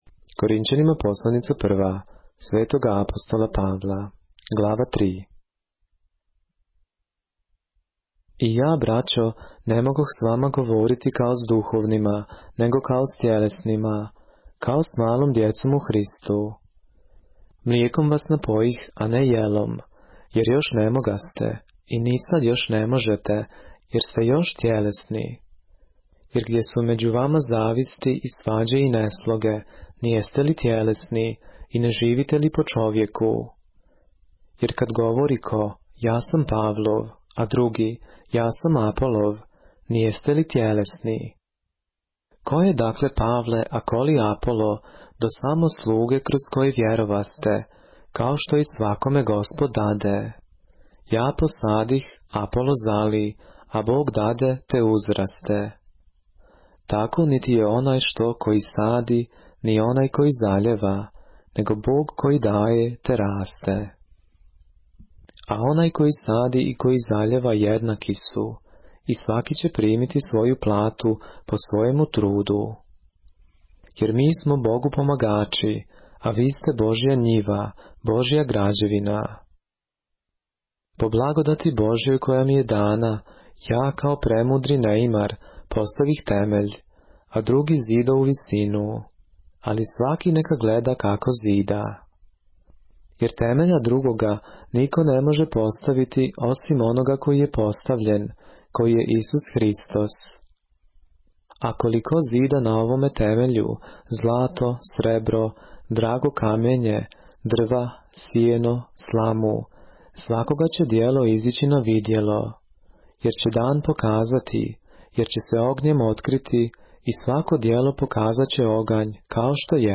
поглавље српске Библије - са аудио нарације - За мудрост овог света лудост. 1. Korinćanima - 1 КОРИНЋАНИМА 3